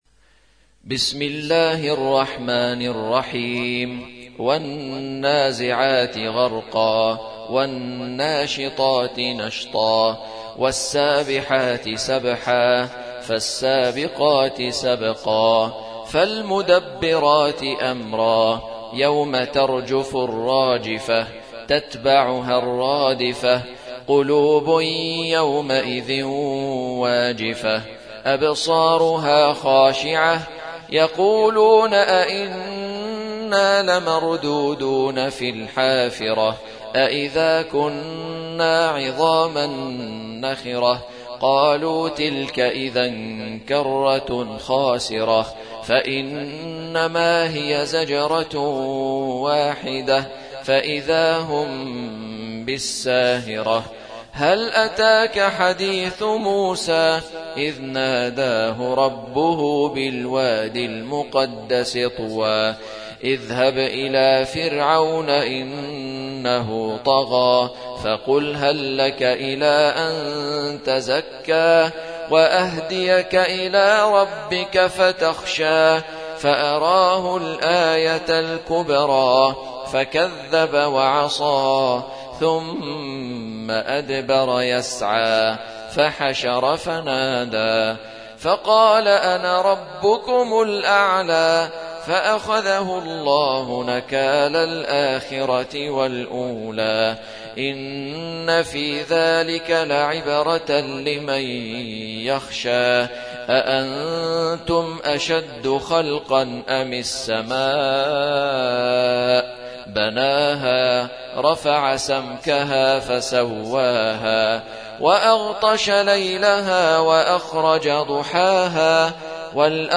Surah Sequence تتابع السورة Download Surah حمّل السورة Reciting Murattalah Audio for 79. Surah An-Nazi'�t سورة النازعات N.B *Surah Includes Al-Basmalah Reciters Sequents تتابع التلاوات Reciters Repeats تكرار التلاوات